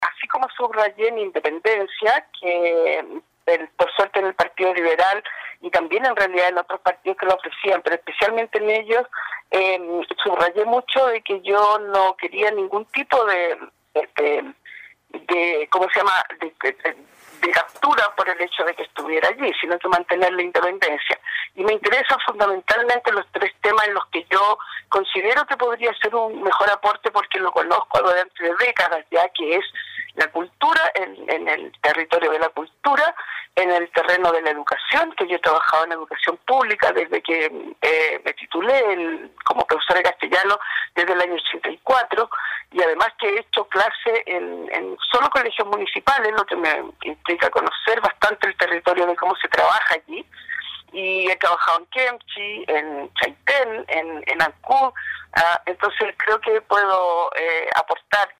En entrevista con radio Estrella del Mar indicó que fue invitada a participar de este proceso por una nueva Constitución por el partido Liberal